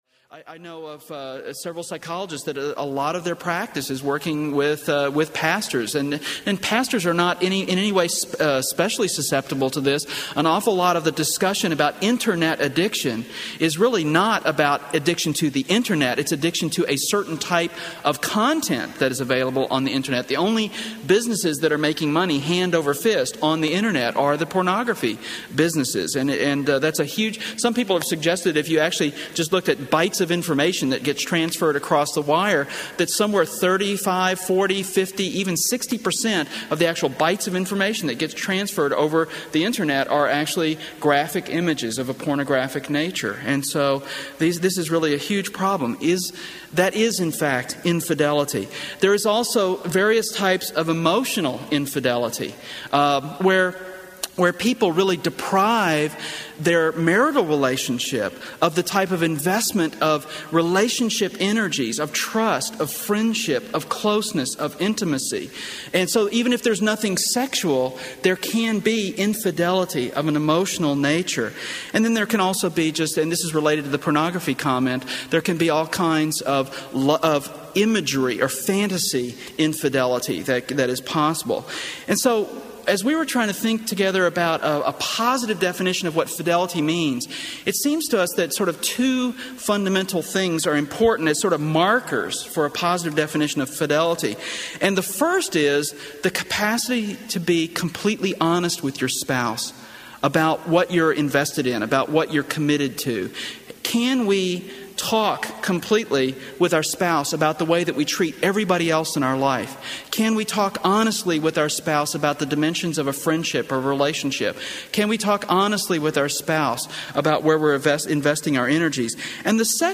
Narrator